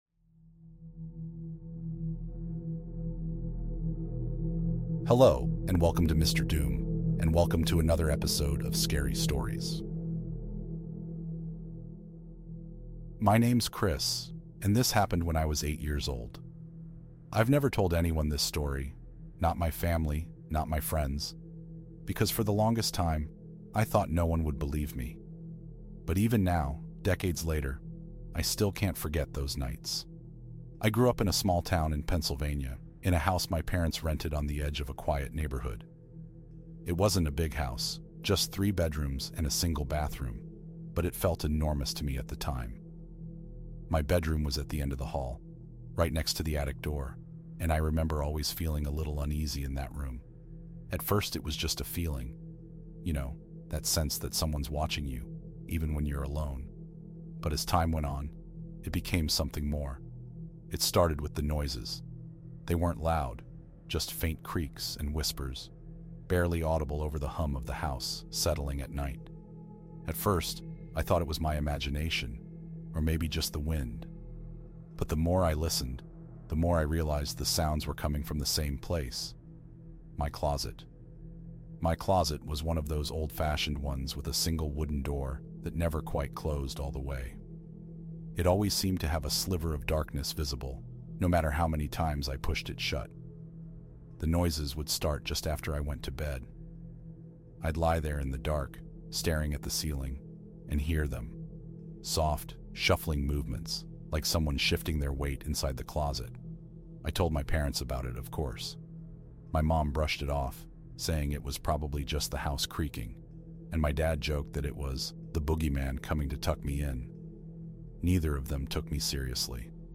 Ep. 3 - Night Alone Horror Stories - With Rain Background